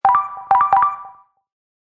kitchen_alarm.mp3